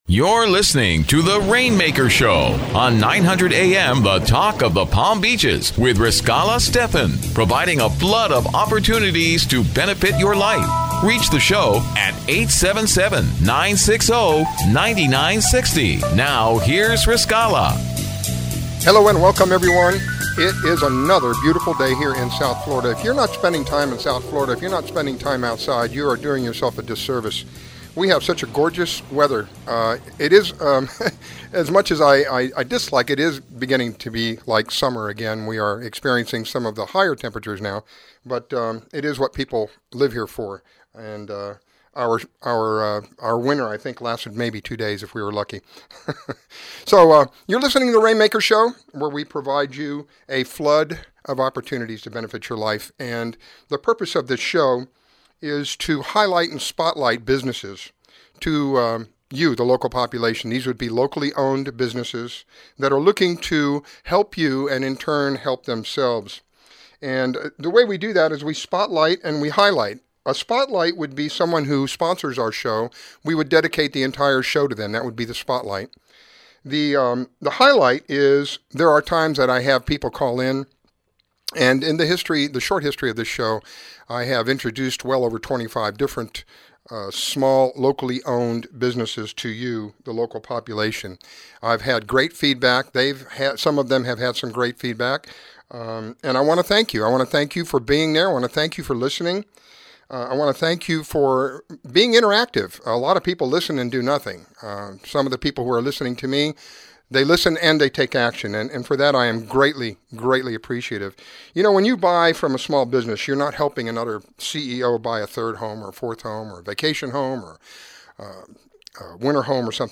Interviewing entrepreneurs and business owners that will provide business opportunities to people seeking extra income, financial security, self-employment benefits and much more!